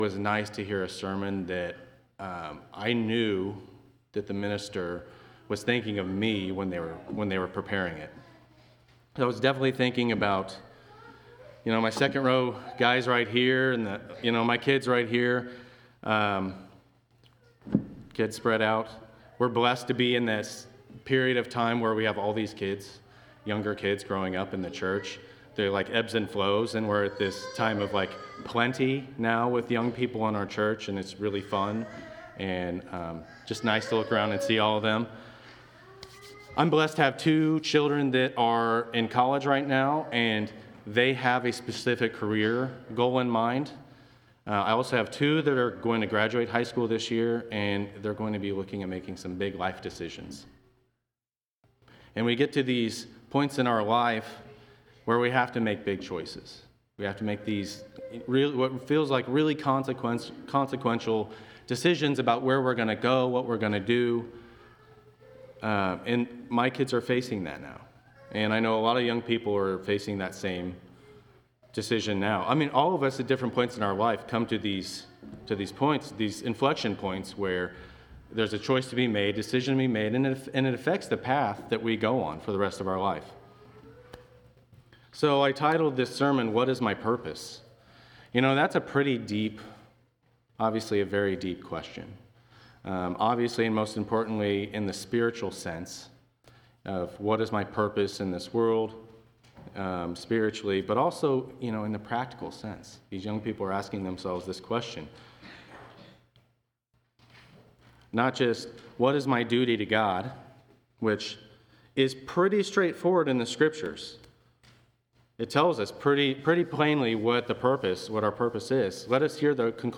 Location: Temple Lot Congregation